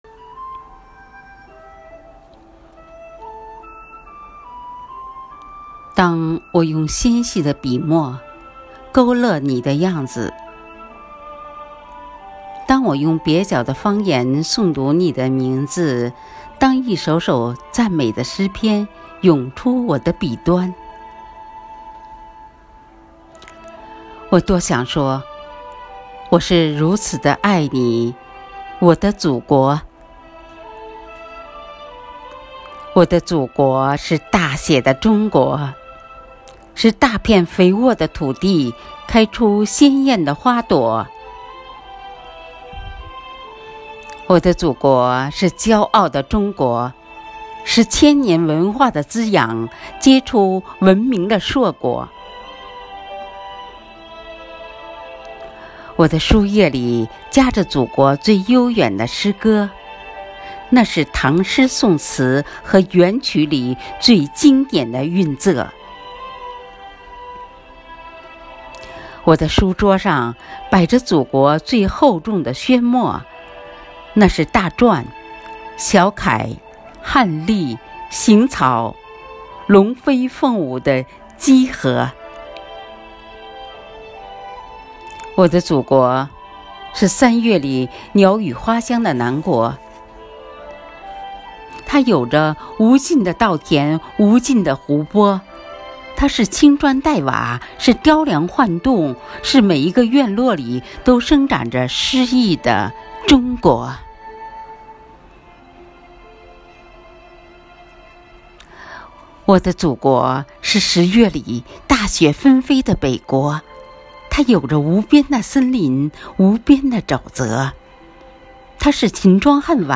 《大写的中国》独诵